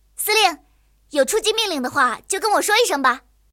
野牛司令部语音2.OGG